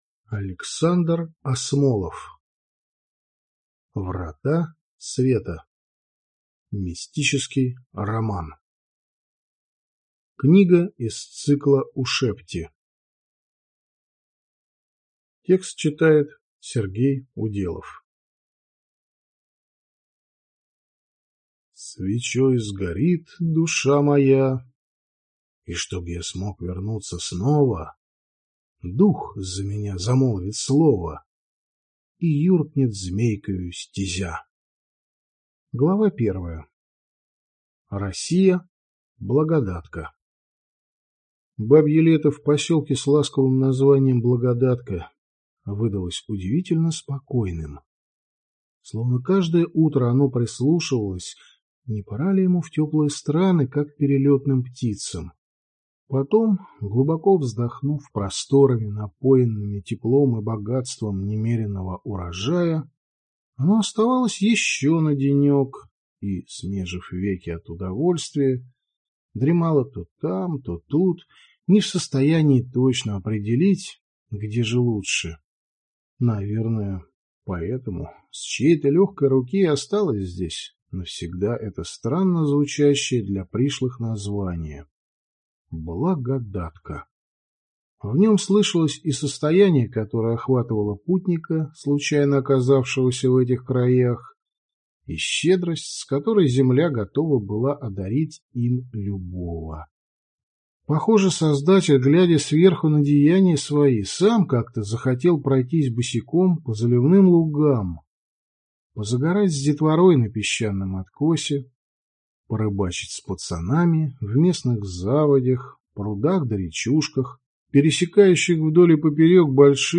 Аудиокнига Врата Света | Библиотека аудиокниг